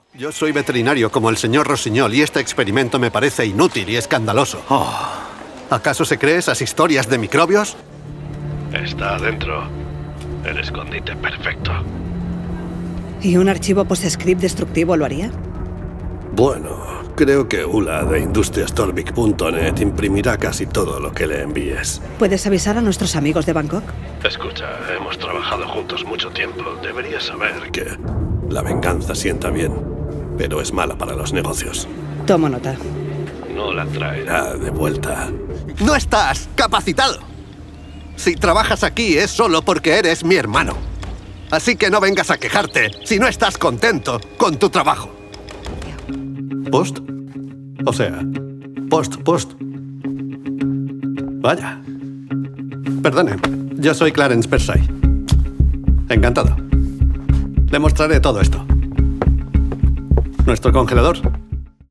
spanish castilian voice talent, spanish freelance voice over. locutor andaluz
kastilisch
Sprechprobe: Sonstiges (Muttersprache):